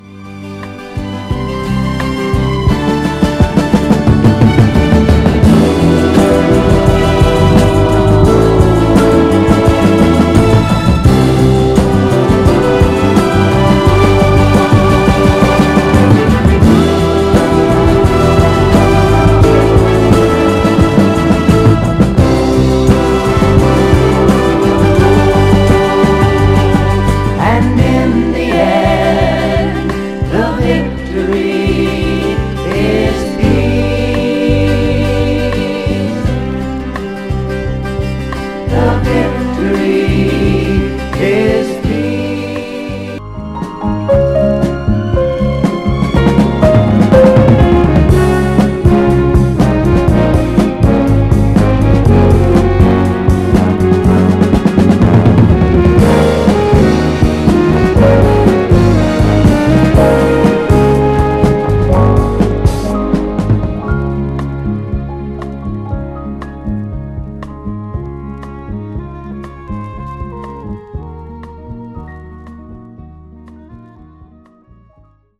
ライトでポップなサウンドとスリリングなブレイクビーツ感が同居する、サンプリング用グルーヴにもオススメな一枚です。
盤は細かい表面スレありますが、音への影響は少なくプレイ概ね良好です。
※試聴音源は実際にお送りする商品から録音したものです※